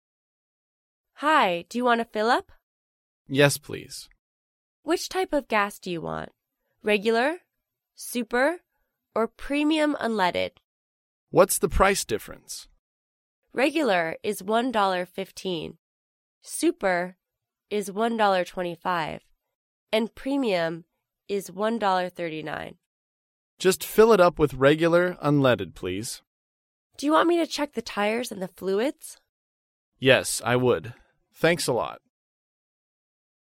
高频英语口语对话 第497期:询问汽油价格 听力文件下载—在线英语听力室